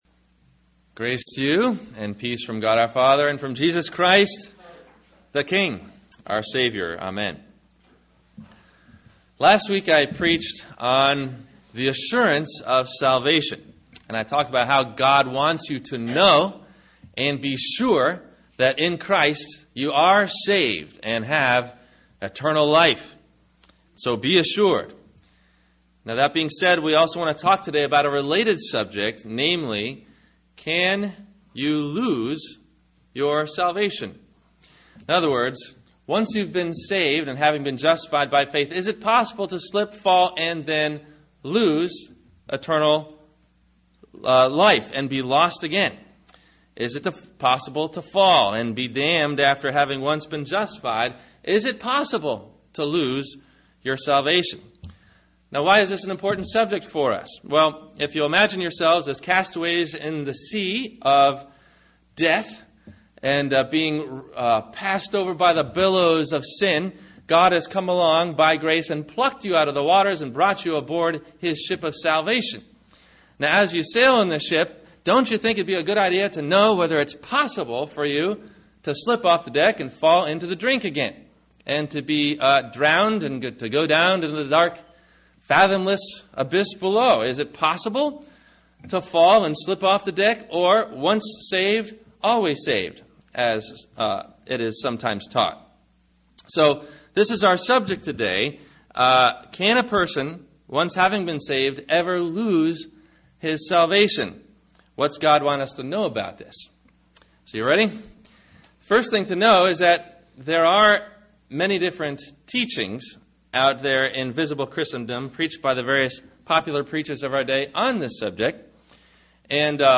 Martin Luther Got in Trouble Over One Little Word, What was It? (God’s Fantastic Four) – Sermon – October 25 2015